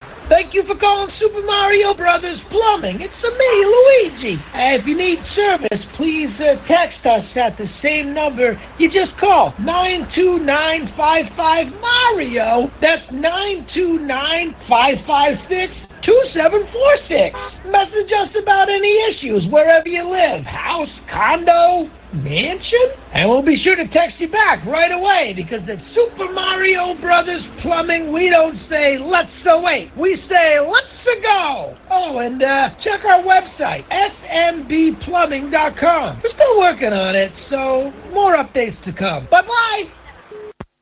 The response given by Luigi by calling "929-55-MARIO"
TSMBM_Phone_Call.oga.mp3